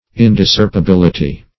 Search Result for " indiscerpibility" : The Collaborative International Dictionary of English v.0.48: Indiscerpibility \In`dis*cerp`i*bil"i*ty\, Indiscerptibility \In`dis*cerp`ti*bil"i*ty\, n. The state or quality of being indiscerpible.